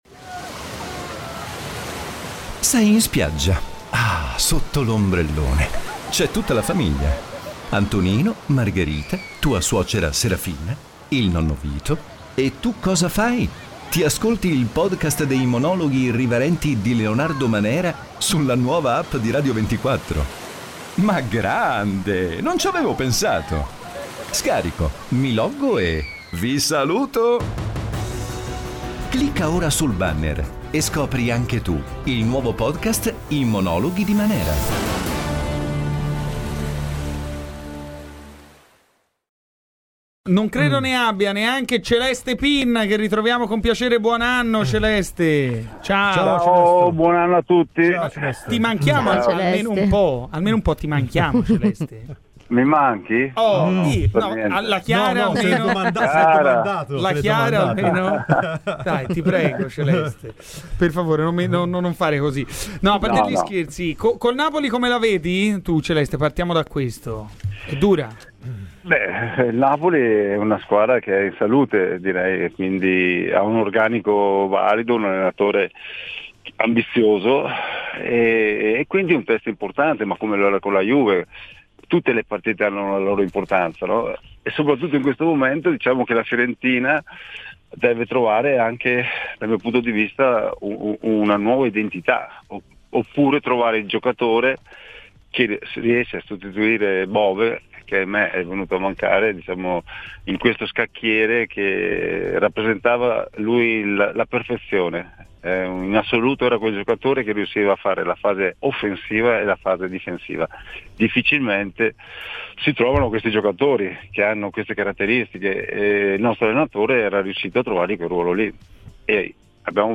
Nel pomeriggio di Radio FirenzeViola spazio a Celeste Pin.